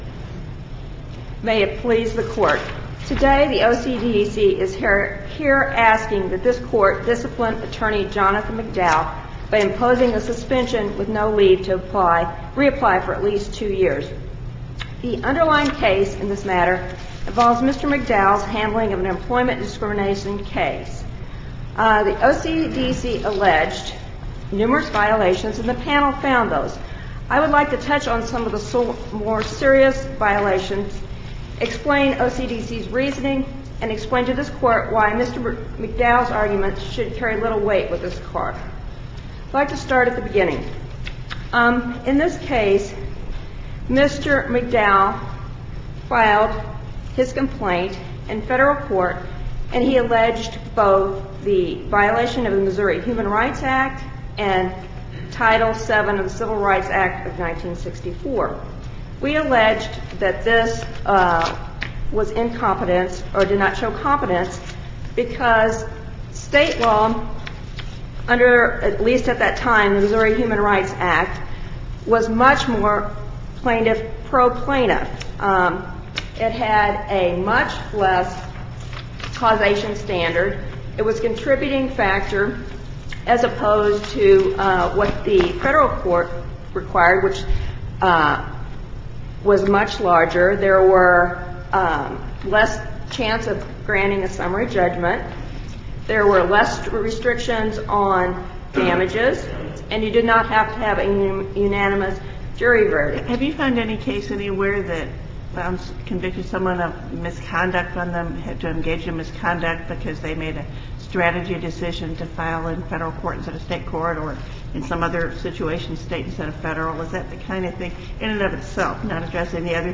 MP3 audio file of arguments in SC96639
Challenge to dismissal of petition seeking recalculation of prison sentence Listen to the oral argument: SC96639 MP3 file